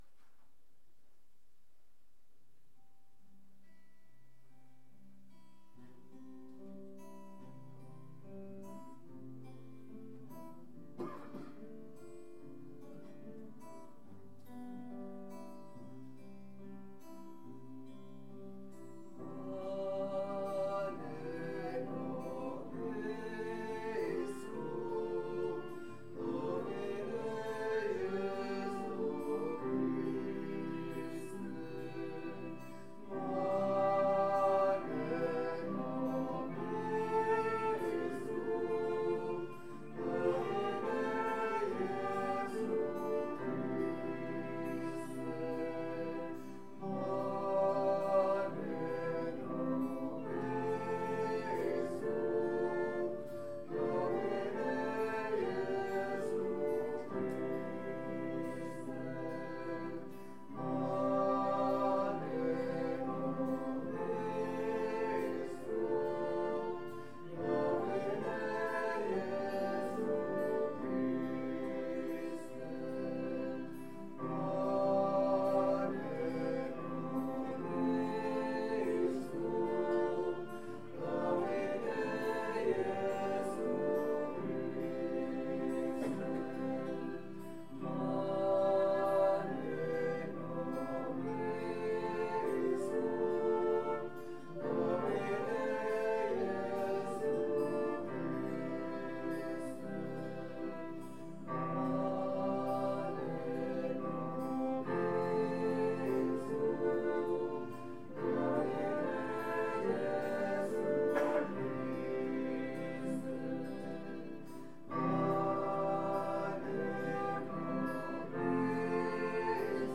Pregària de Taizé
Capella dels Salesians - Diumenge 26 d'abril de 2015